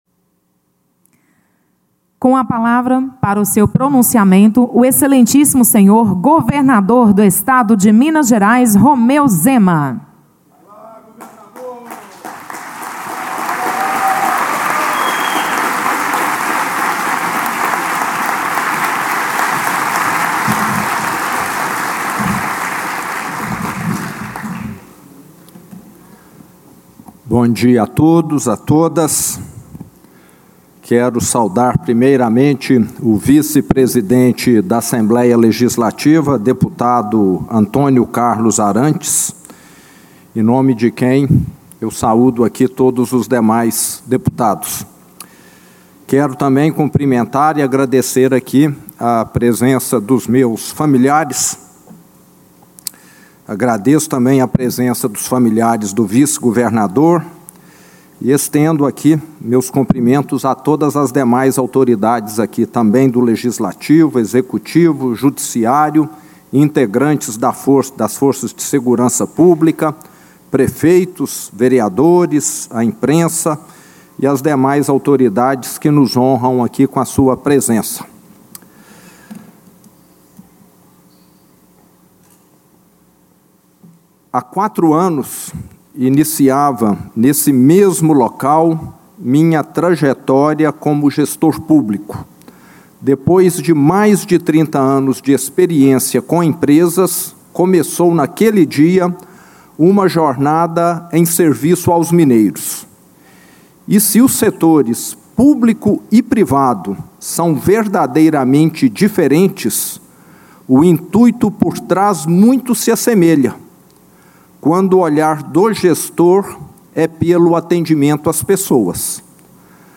Após reemposado na ALMG, o governador falou da Tragédia de Brumadinho, da pandemia de Covid-19 e outros momentos que impactaram a gestão estadual, fazendo balanço dos primeiros quatro anos de governo.
Íntegra - Em seu discurso de posse, Romeu Zema aponta desafios do primeiro mandato e fala em harmonia entre os poderes